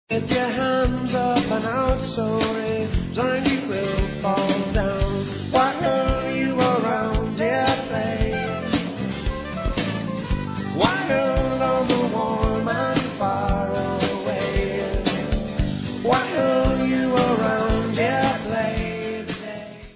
Jamming poprock world beat flavour